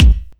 KICK145.wav